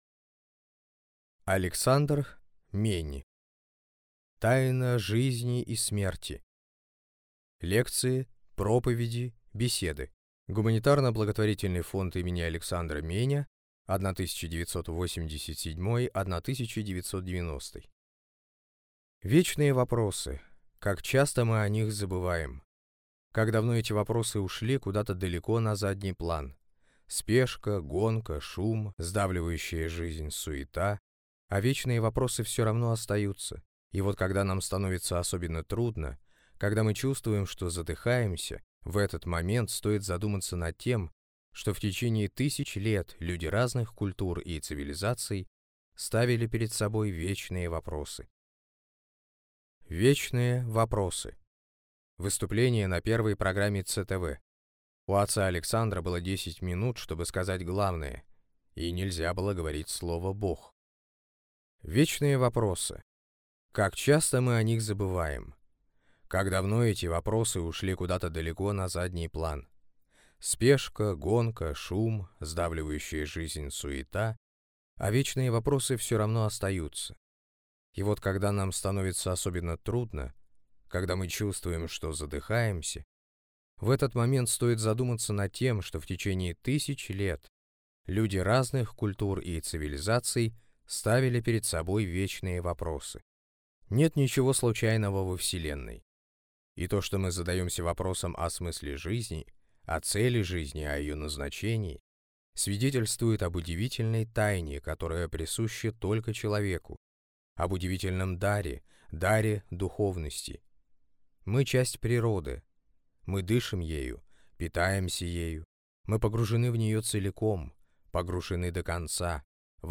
Аудиокнига Тайна жизни и смерти. Лекции, проповеди, беседы | Библиотека аудиокниг